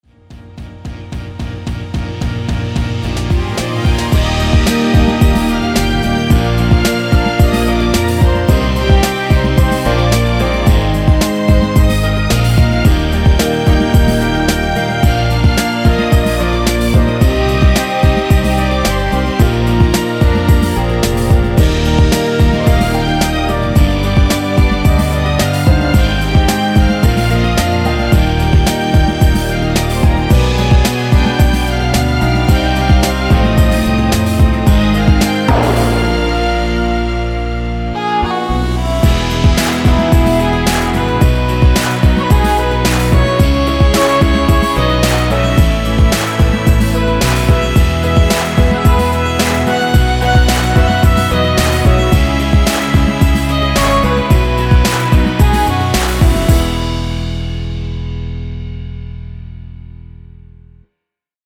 원곡에서 4분 45초까지하고 엔딩을만들어 놓았습니다.(미리듣기 확인)
원키에서(-2)내린 멜로디 포함된 MR입니다.
앞부분30초, 뒷부분30초씩 편집해서 올려 드리고 있습니다.
중간에 음이 끈어지고 다시 나오는 이유는